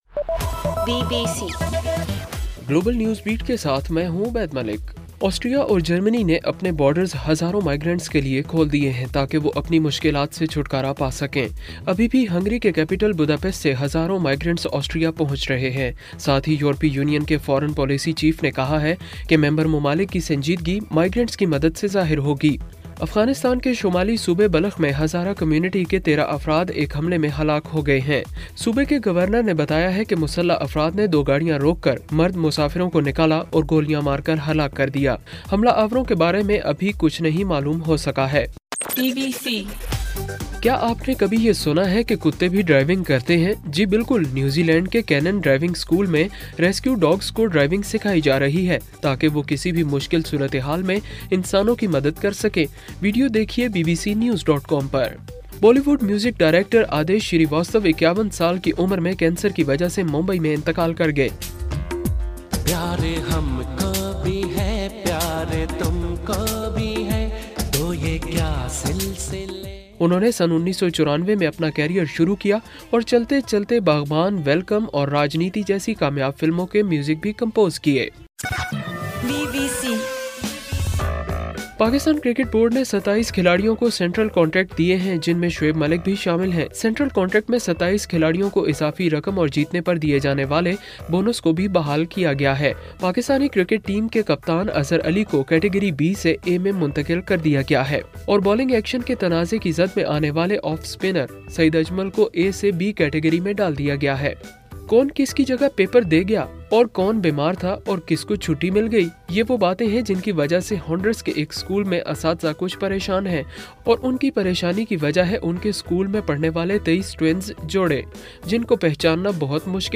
ستمبر 5: رات 10 بجے کا گلوبل نیوز بیٹ بُلیٹن